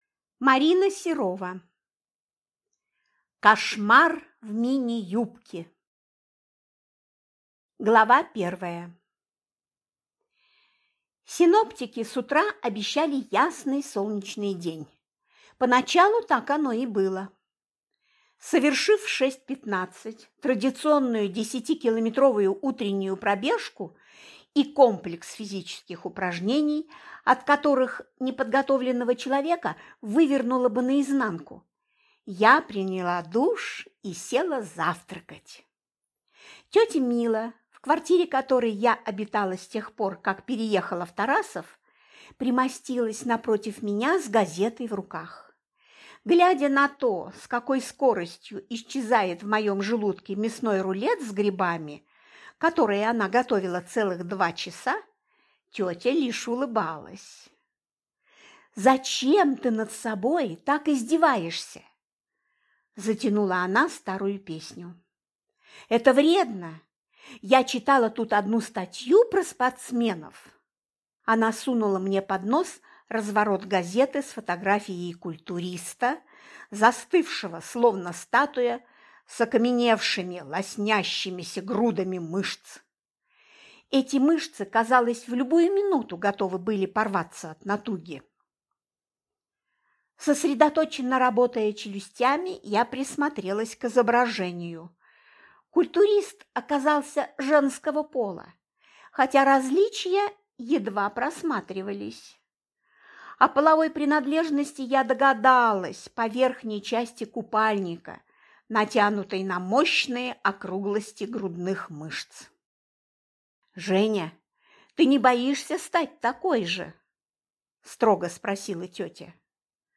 Аудиокнига Кошмар в мини-юбке | Библиотека аудиокниг